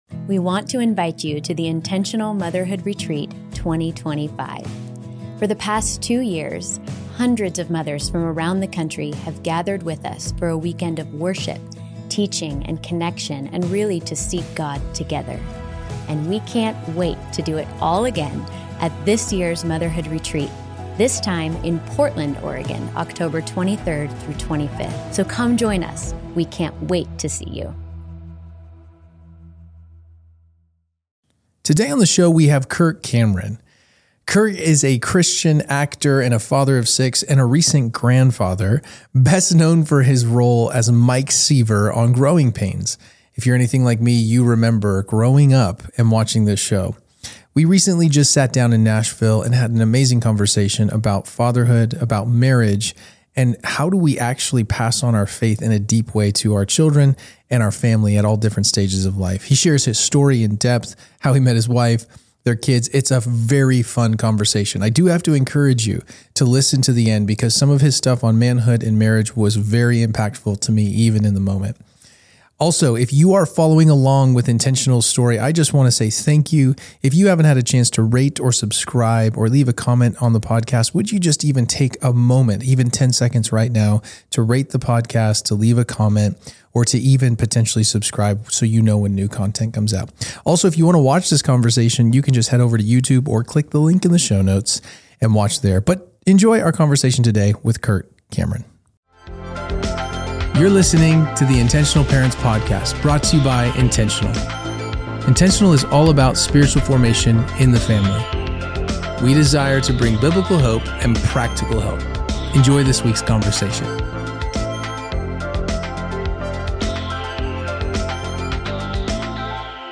Character, Dying to Self in Marriage + Modeling Biblical Manhood (Interview with Kirk Cameron)